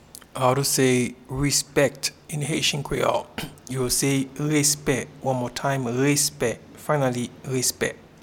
Pronunciation and Transcript:
Respect-in-Haitian-Creole-Respe.mp3